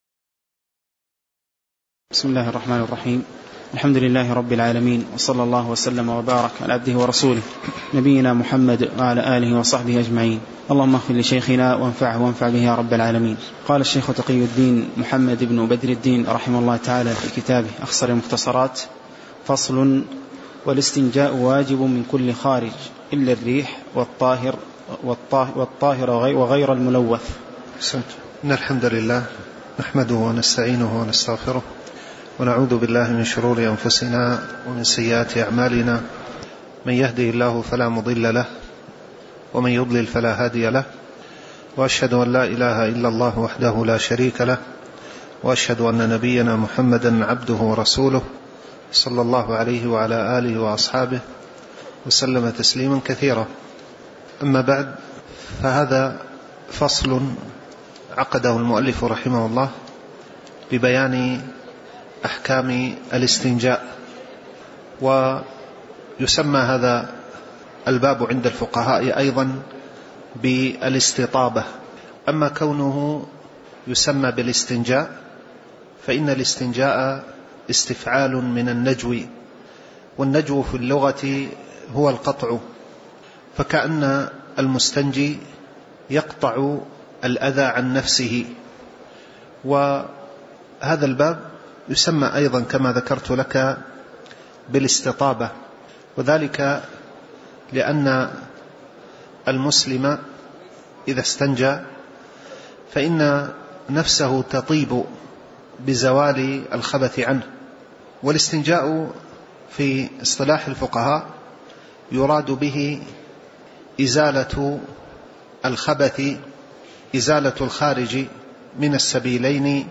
تاريخ النشر ٢٨ محرم ١٤٣٩ هـ المكان: المسجد النبوي الشيخ